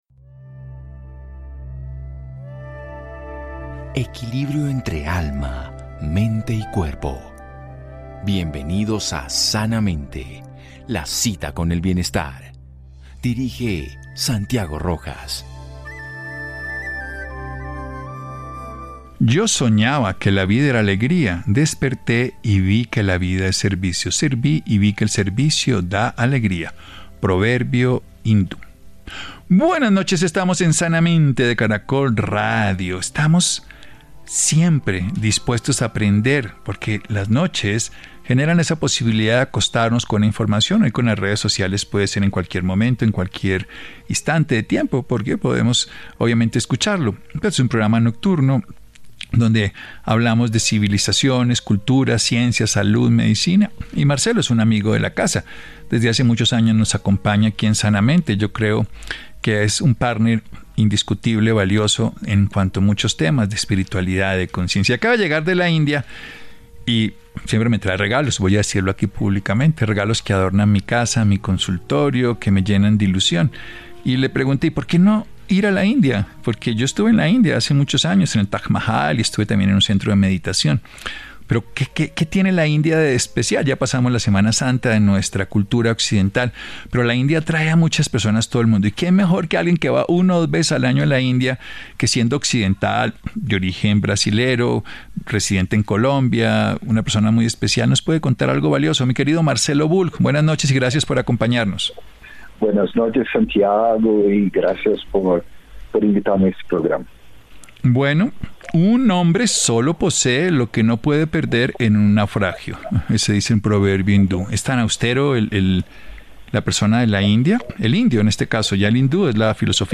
Vivir sin premura: conectarse con el ser - Entrevista